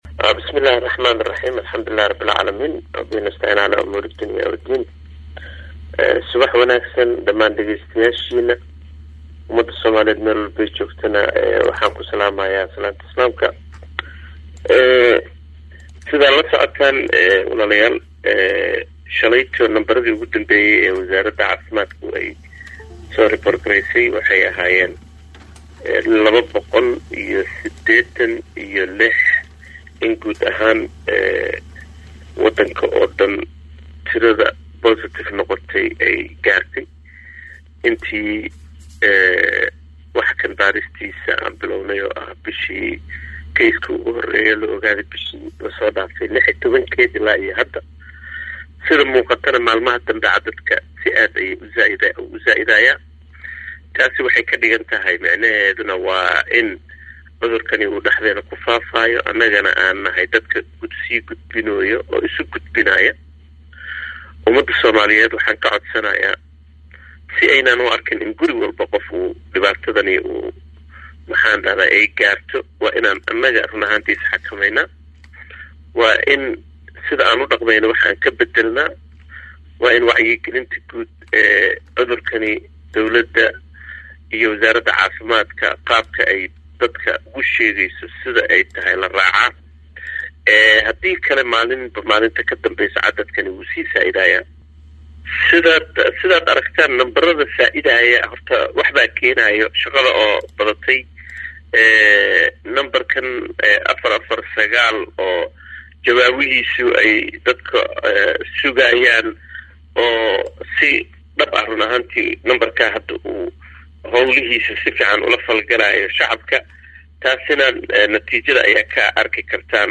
Halkaan hoose ka dhageyso Wareysiga